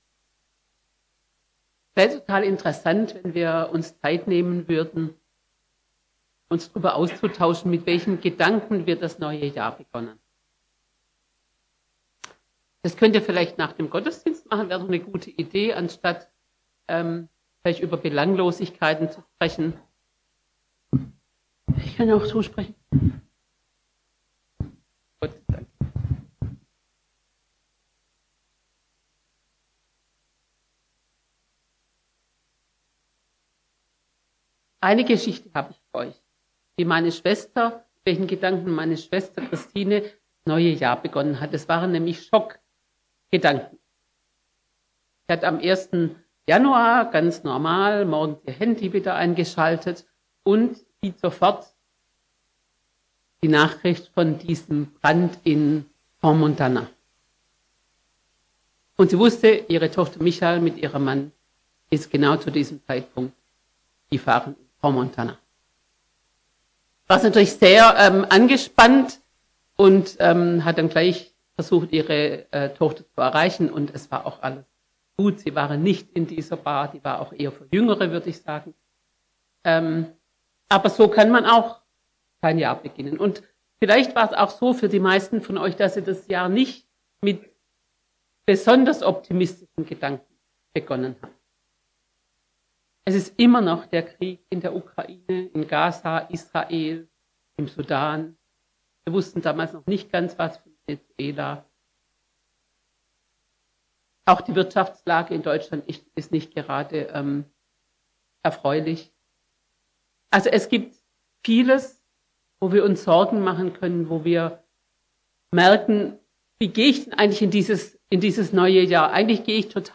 14-25 ~ Predigten aus der Fuggi Podcast